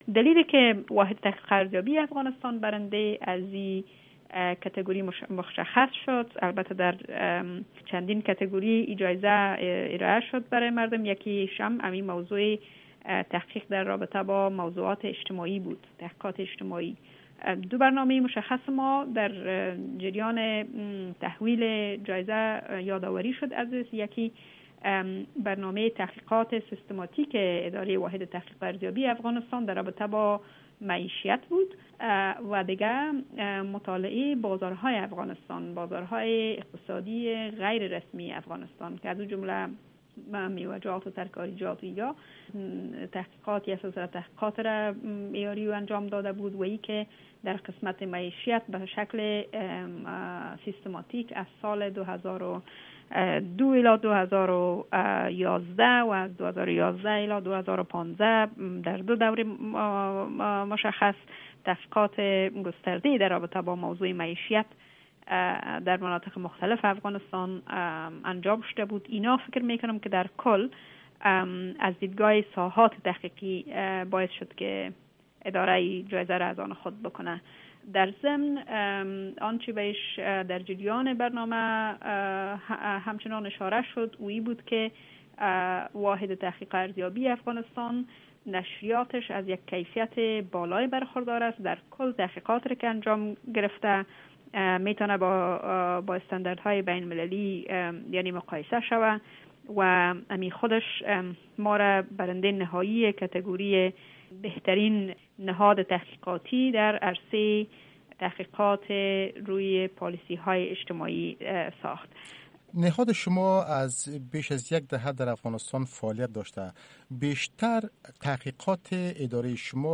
مصاحبۀ کامل